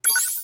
select-expand.wav